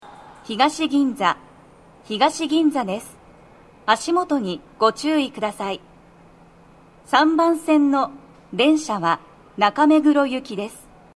スピーカー種類 両面箱型
🎵到着放送
足元注意喚起放送が付帯されており、多少の粘りが必要です。
到着放送1